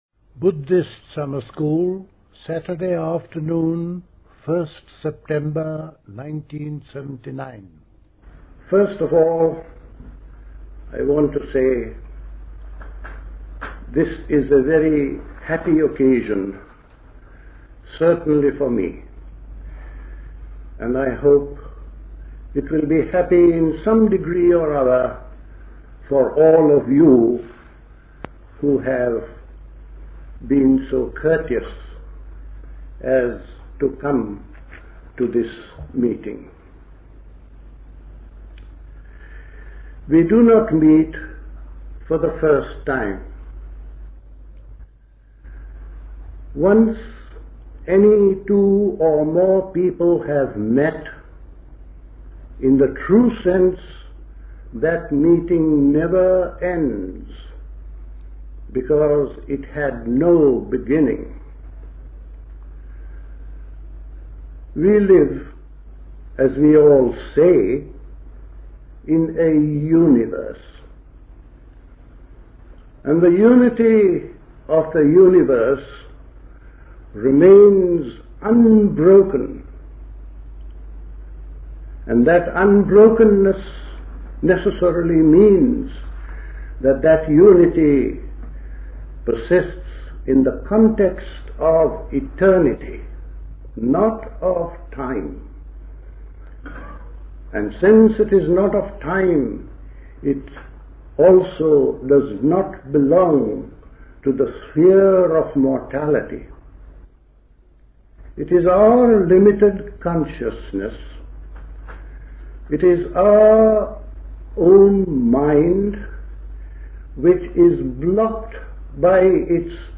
A talk
at High Leigh Conference Centre, Hoddesdon, Hertfordshire
The Buddhist Society Summer School